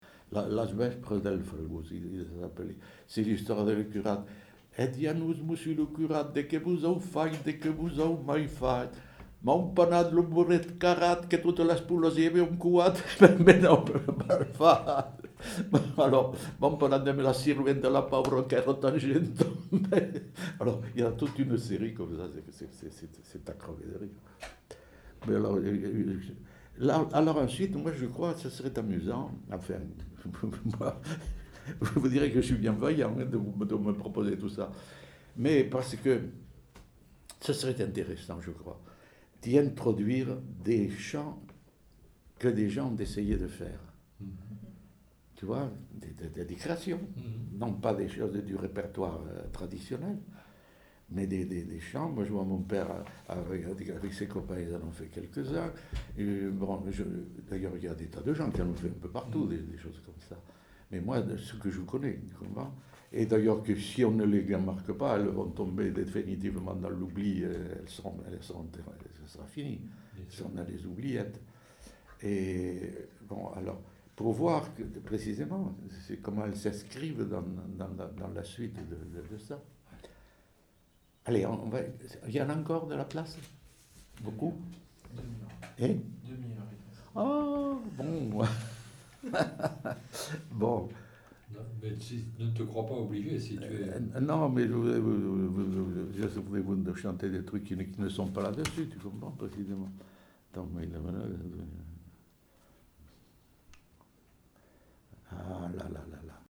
Aire culturelle : Rouergue
Genre : chant
Effectif : 1
Type de voix : voix d'homme
Production du son : chanté